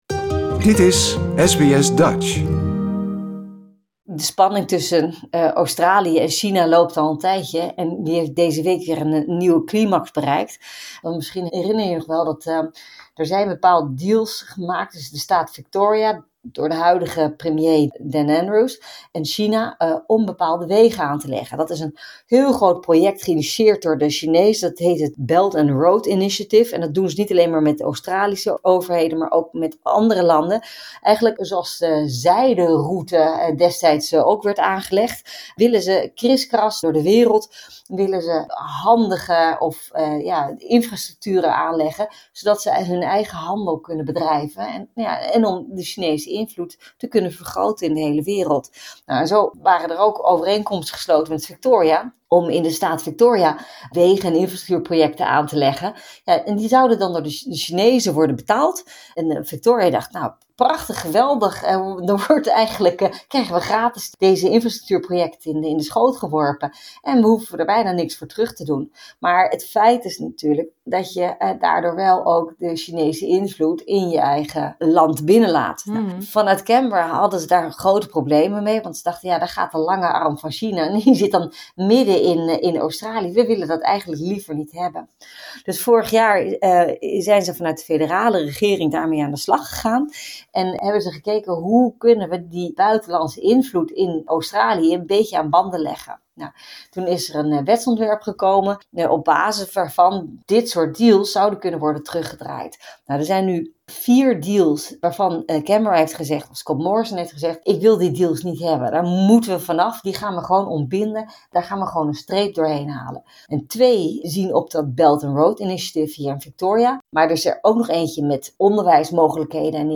Politiek commentator